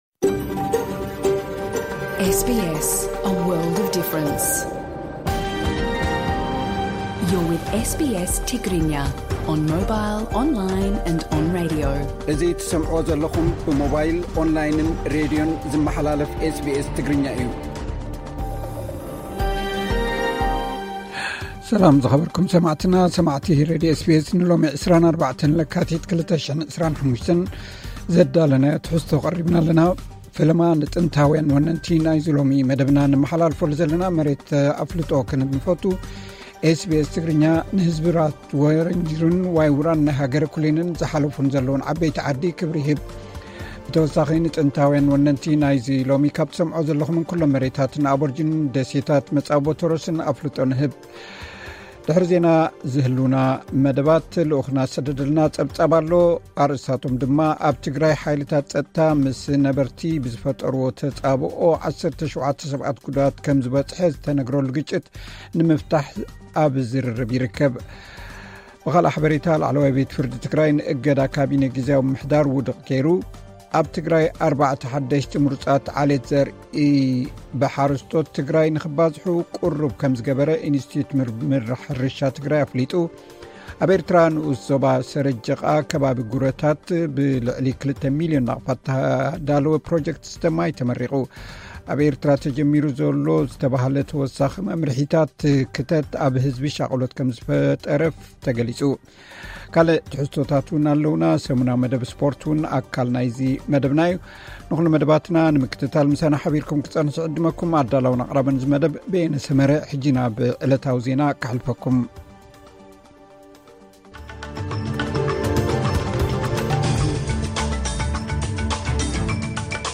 ቀጥታ ምሉእ ትሕዝቶ ኤስ ቢ ኤስ ትግርኛ (24 ለካቲት 2025)
ኣርእስታት ዜና፥